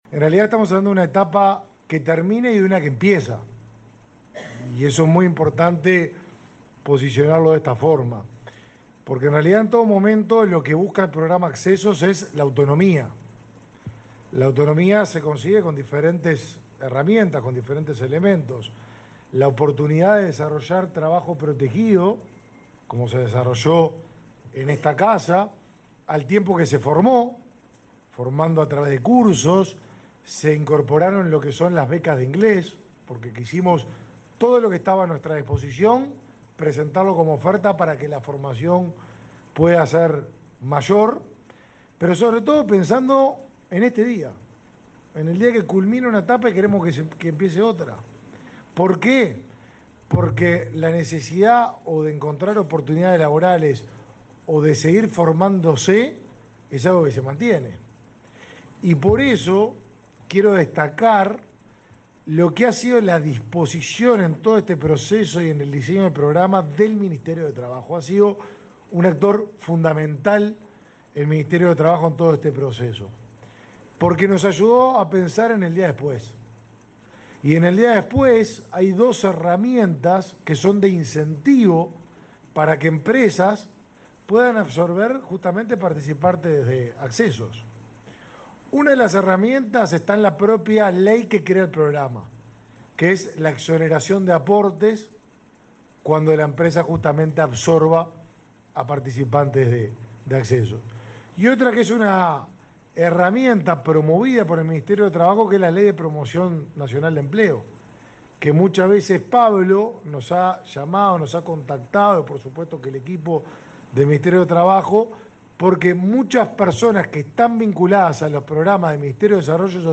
Palabras de los ministros de Desarrollo Social y Trabajo
El ministro de Desarrollo Social, Martín Lema, y su par de Trabajo, Pablo Mieres, participaron en el acto por la finalización del programa Accesos, de